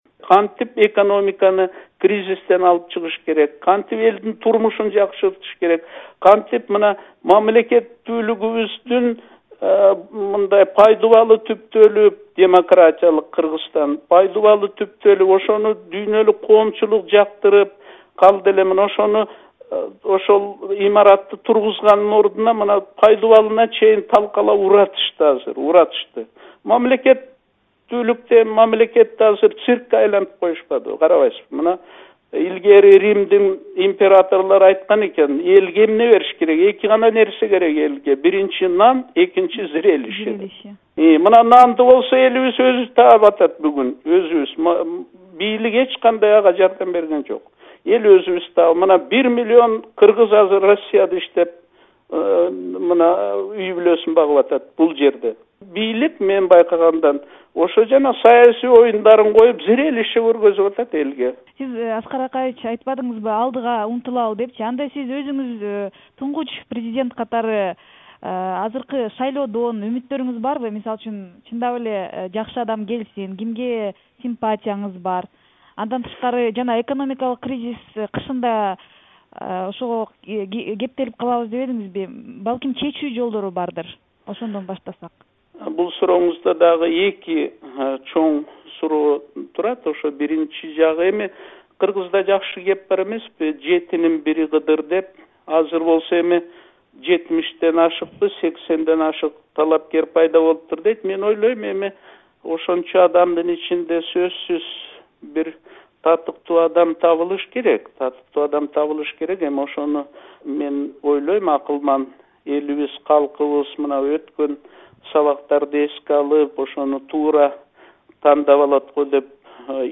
Аскар Акаев менен маек (2-бөлүк)